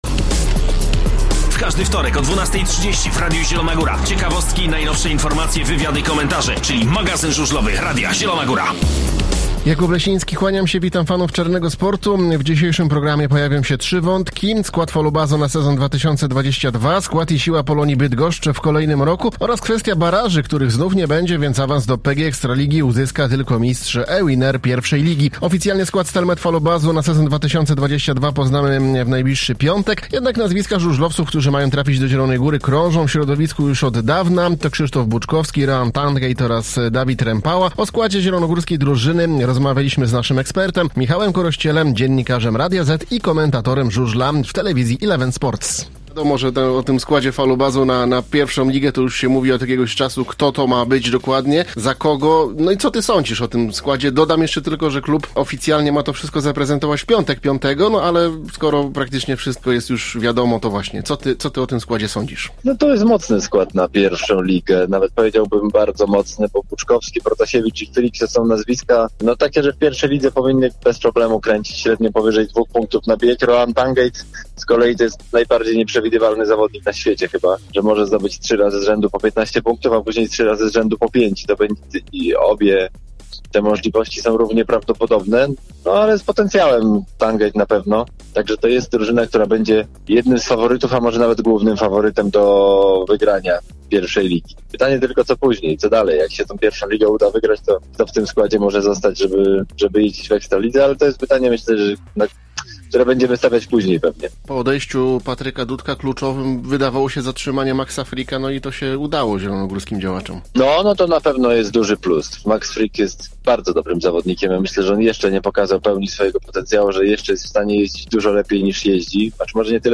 Tym razem w magazynie wystąpili eksperci: